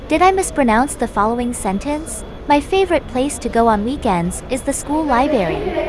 pronounciation0_AirportAnnouncements_1.wav